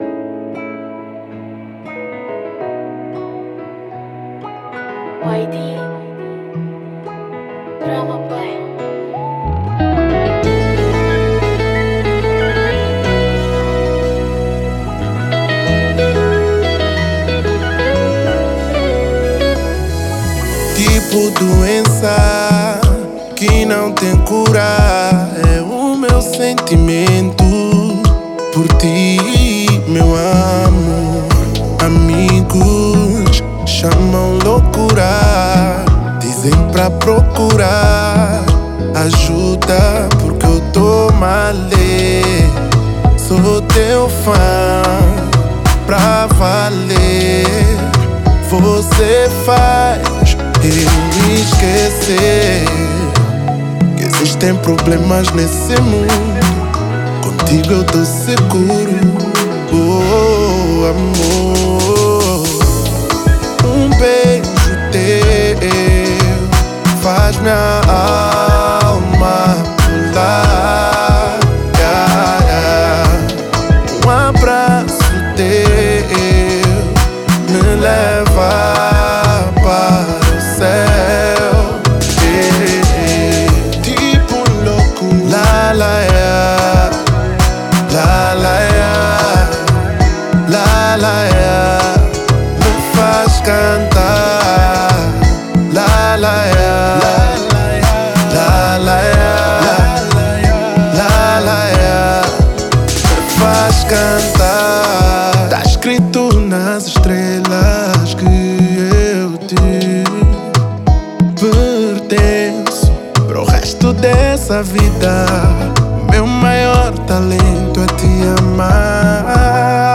Kizomba